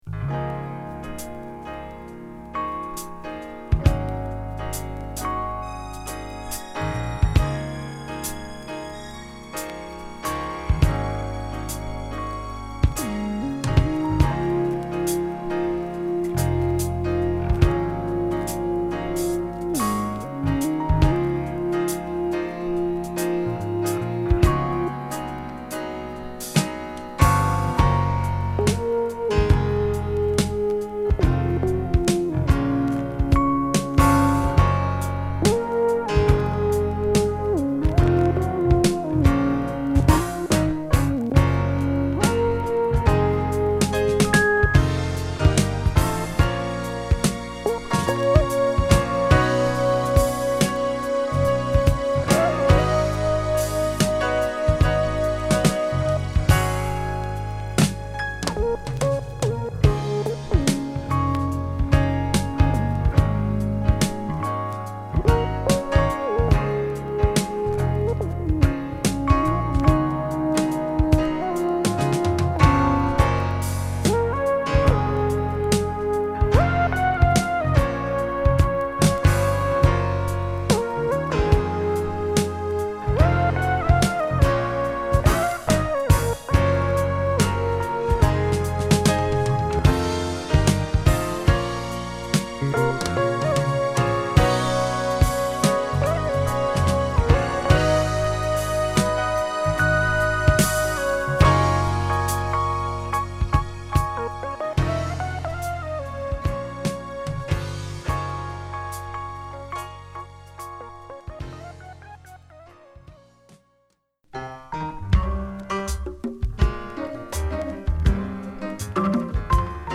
鍵盤奏者のゴスペルアルバムと言う事で全編インスト曲で構成。
ゴスペルのインスト作品は珍しいですね。